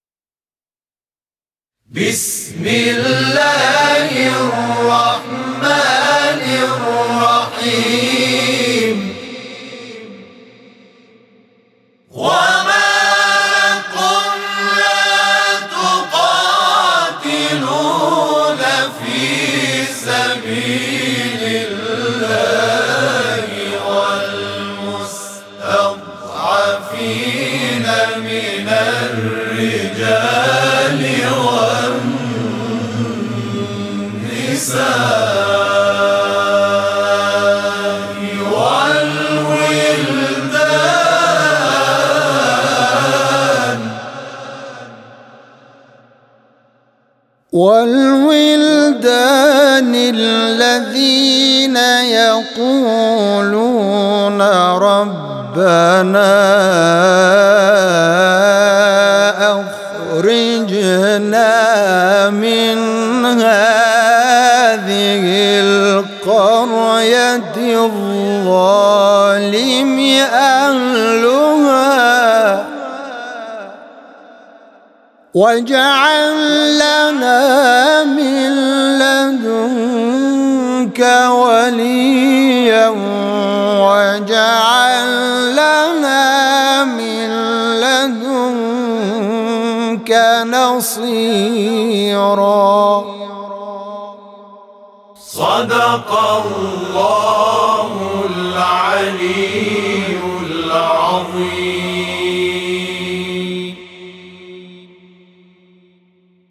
صوت همخوانی آیه 75 سوره «نساء» از سوی گروه تواشیح «محمد رسول‌الله(ص)»